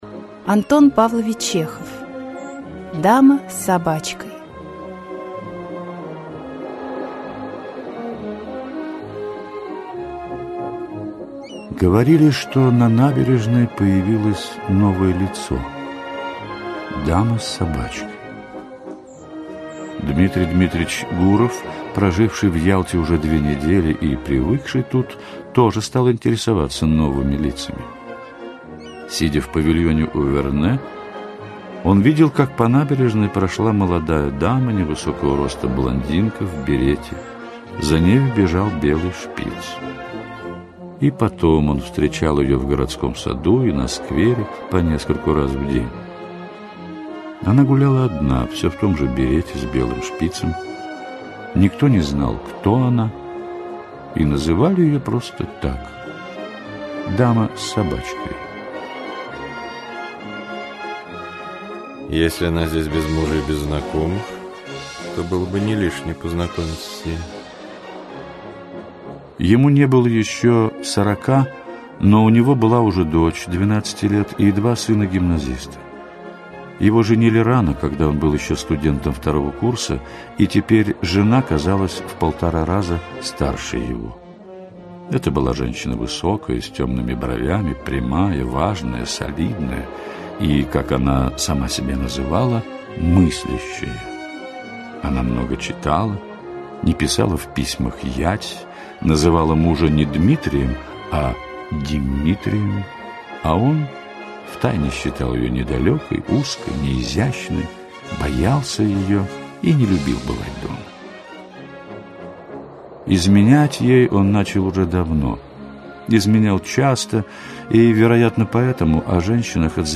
Аудиокнига Дама с собачкой. Аудиоспектакль | Библиотека аудиокниг
Аудиоспектакль Автор Антон Чехов Читает аудиокнигу Игорь Костолевский.